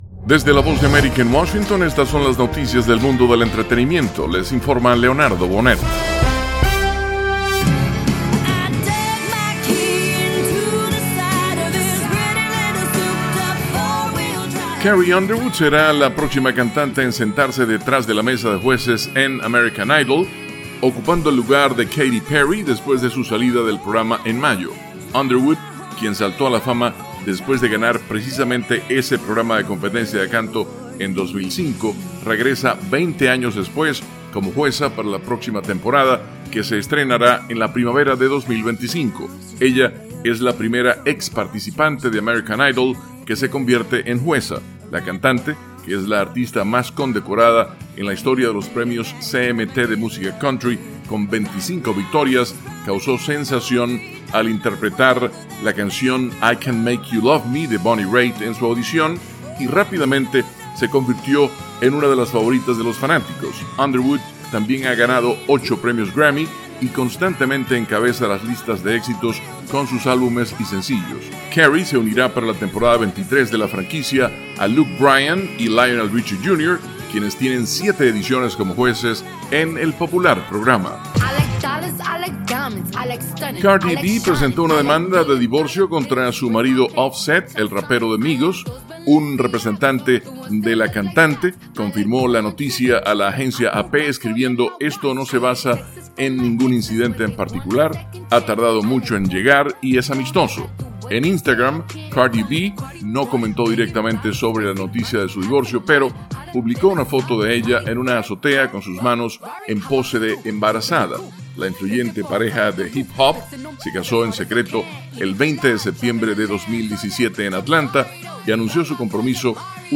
Son las Noticias del Mundo del Entretenimiento